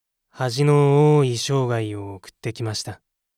パロディ系ボイス素材　2